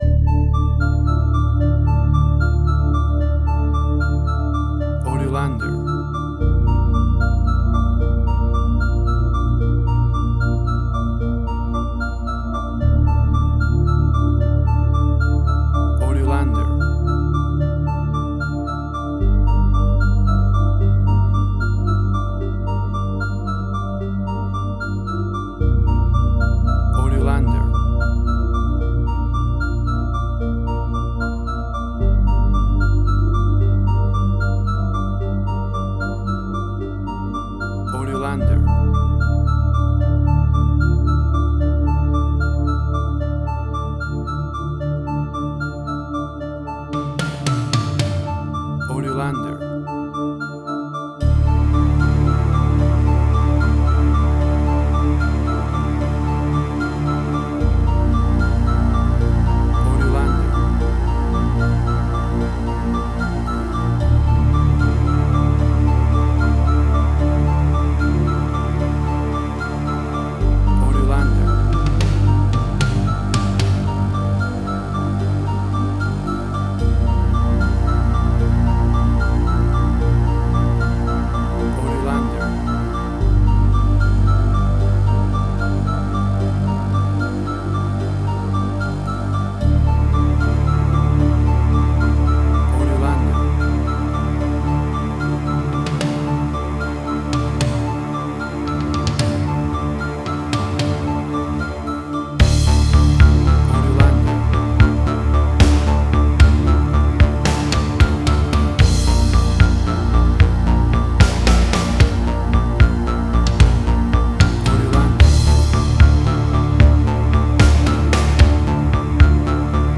Future Retro Wave
New Wave
Tempo (BPM): 75